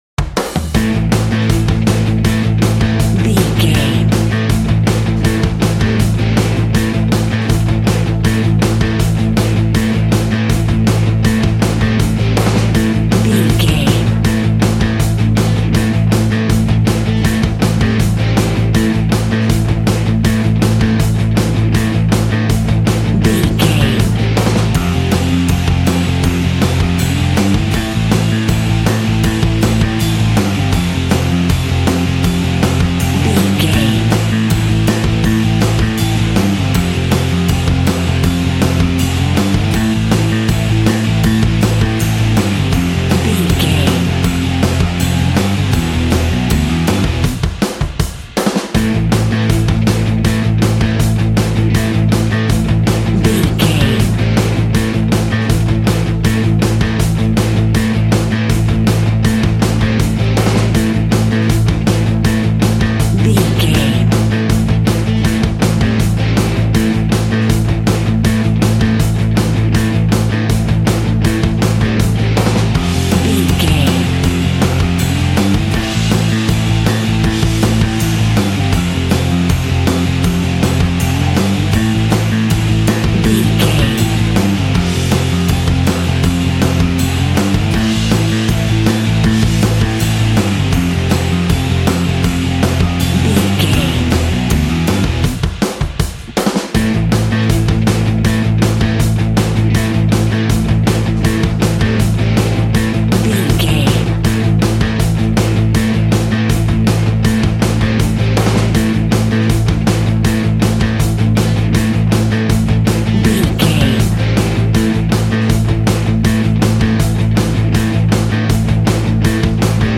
Aeolian/Minor
angry
heavy
aggressive
electric guitar
drums
bass guitar